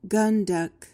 PRONUNCIATION: (GUHN-dek) MEANING: noun: The deck carrying guns on a warship. verb tr.: To falsify or fabricate.